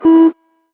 alarm_beep_warning_01.wav